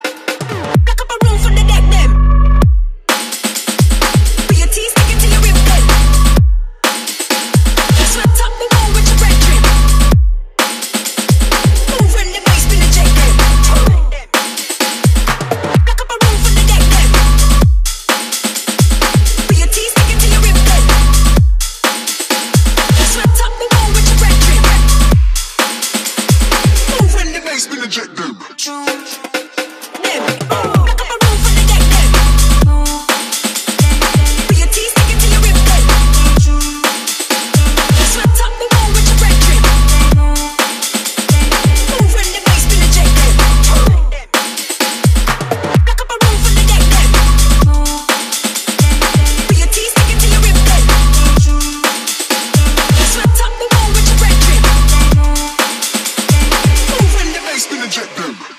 • Качество: 320, Stereo
громкие
мощные
женский голос
Electronic
быстрые
Стиль: breaks / breakbeat